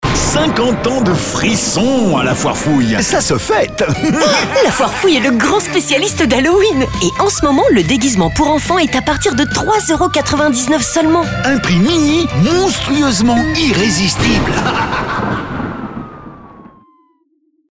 Sono-FF-Halloween-Radio-spot1.mp3